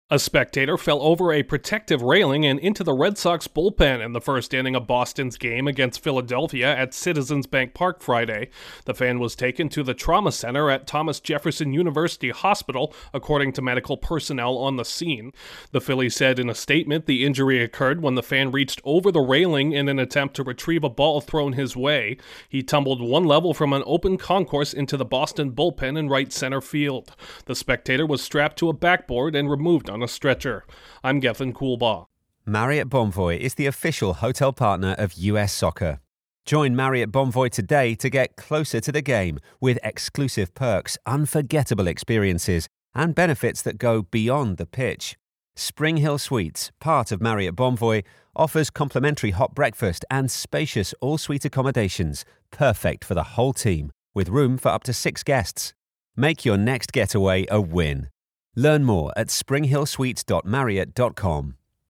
A fan fals from the stands during a Phillies-Red Sox game. Correspondent